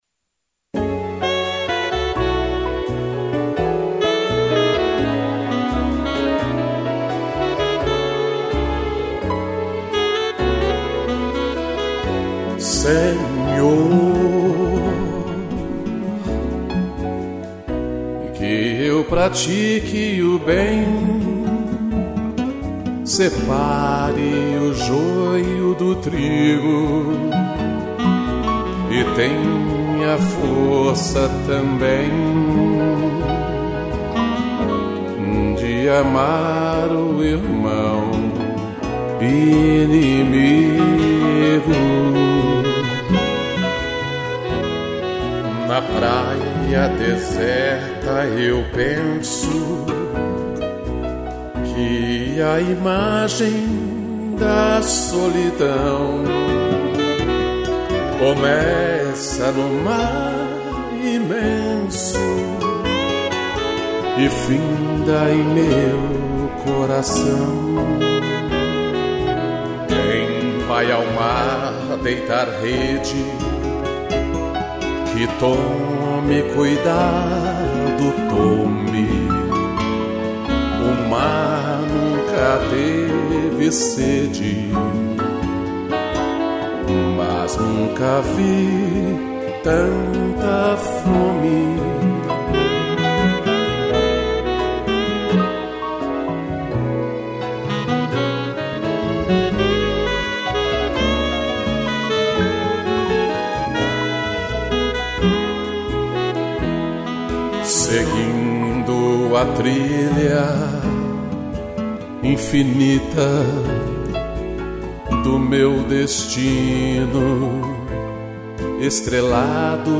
Voz e violão
piano, sax e tutti